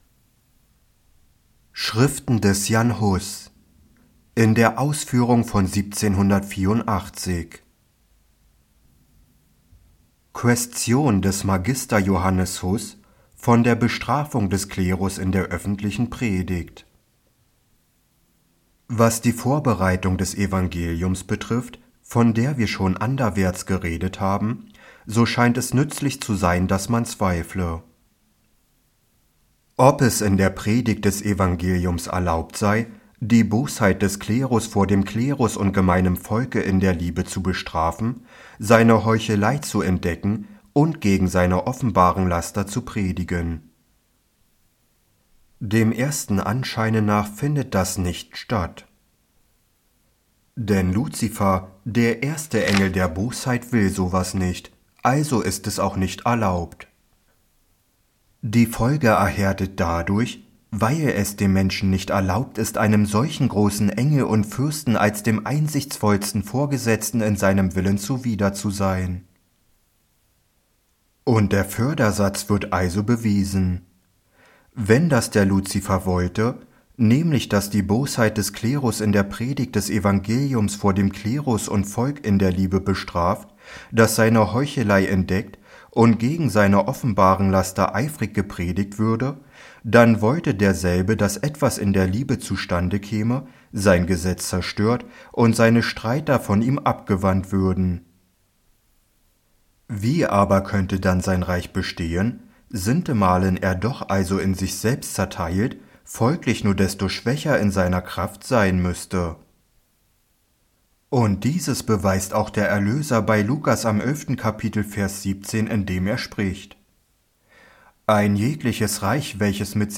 Bei diesem Werk handelt es sich um die geschnittene Fassung von Jan Hus Schriftwerk, welches vom Informationsdienst für ewige Bildung als Hörbuch oder als Video mit Text zum Mitlesen zur Verfügung gestellt wird.